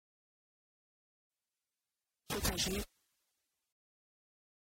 prononciation Potager
potager_mot.mp3